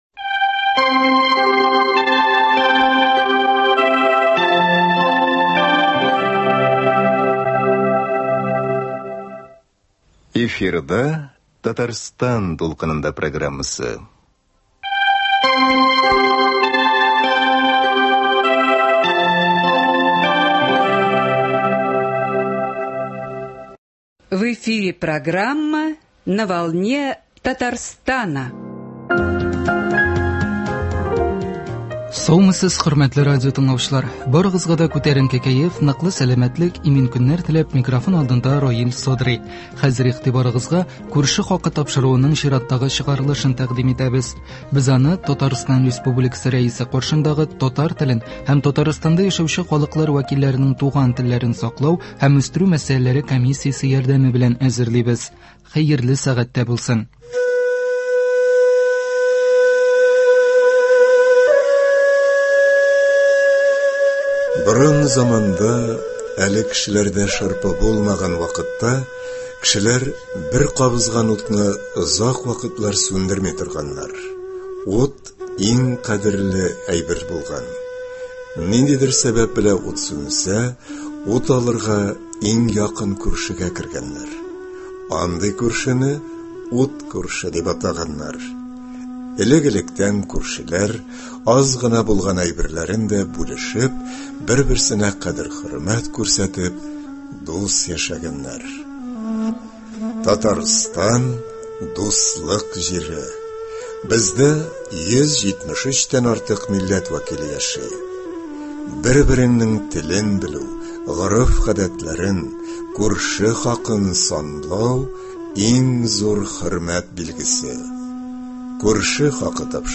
әңгәмәдән ишетә аласыз